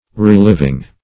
Meaning of reliving. reliving synonyms, pronunciation, spelling and more from Free Dictionary.